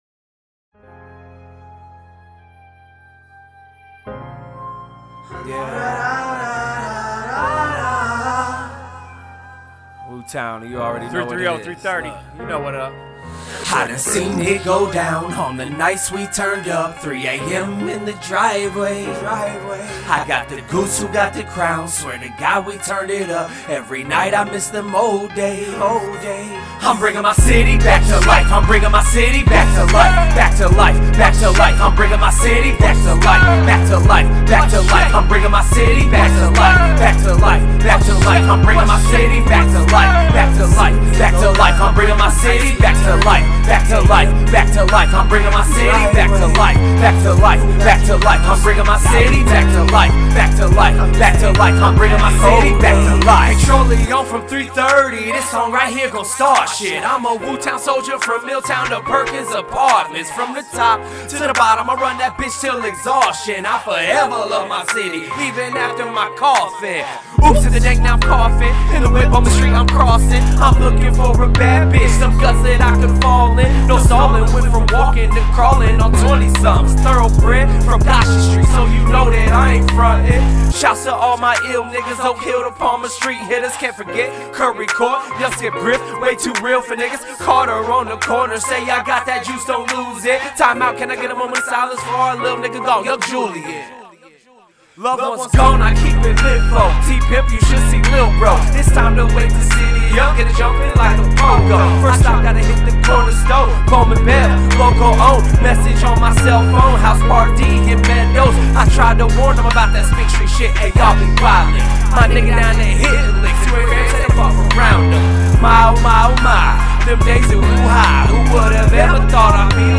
Hiphop
Description : OHIO Hip-Hop Muzik!!!